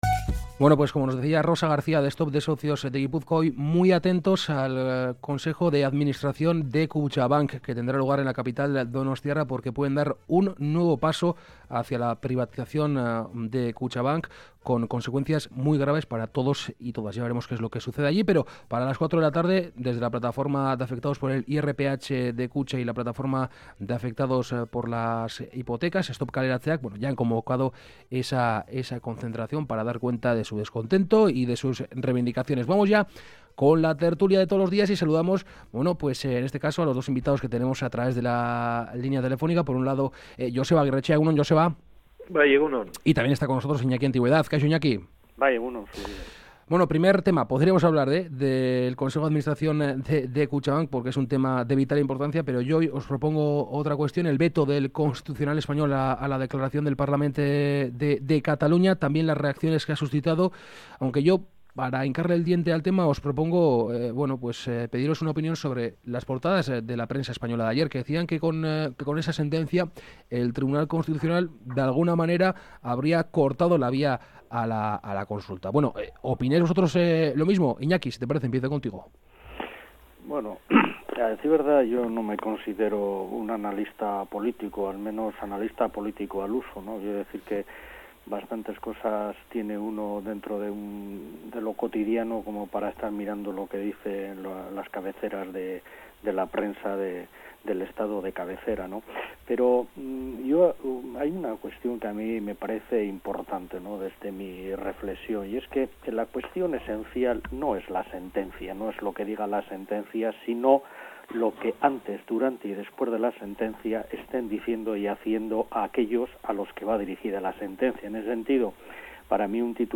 Charlamos y debatimos sobre algunas de las noticias mas comentadas de la semana con nuestros colaboradores habituales. Hoy, participan en la tertulia Iñaki Antigüedad y Joseba Agirretxea.